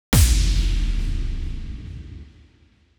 SouthSide Stomp (5) .wav